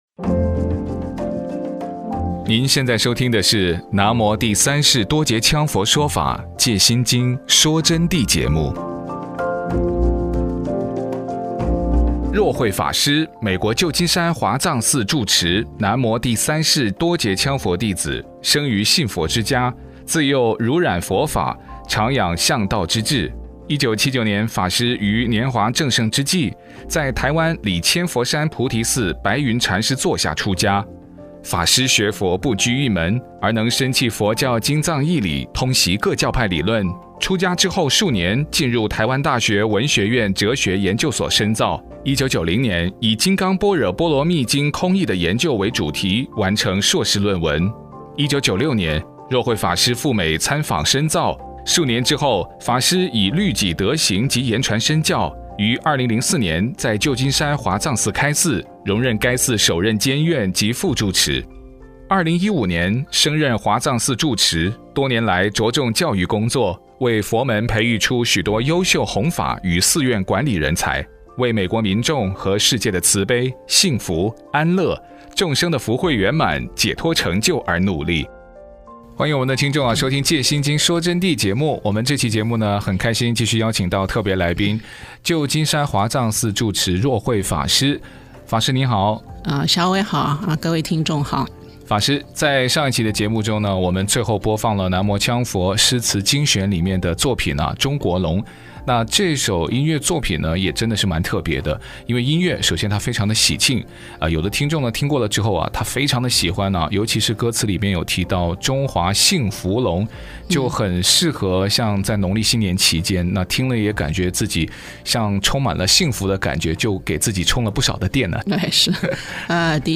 佛弟子访谈（七十四至八十一）